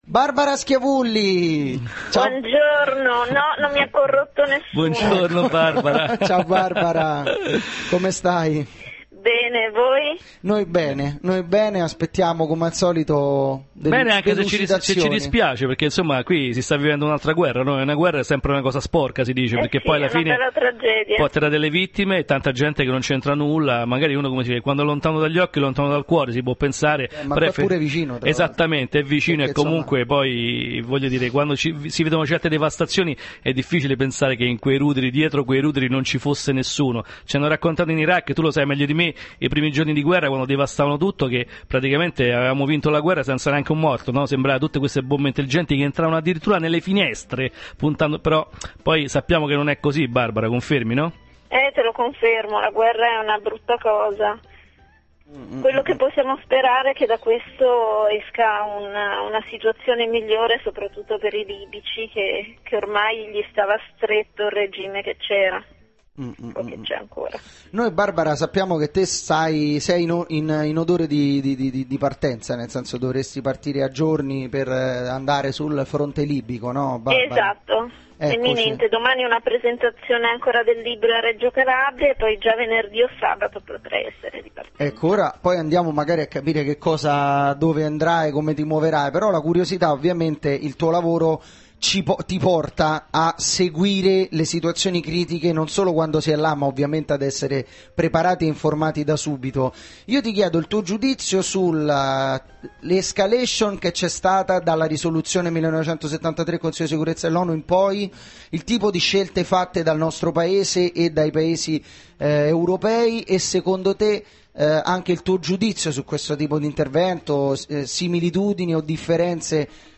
programma radiofonico
Intervento telefonico